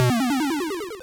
line_clear.wav